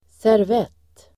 Ladda ner uttalet
Uttal: [serv'et:]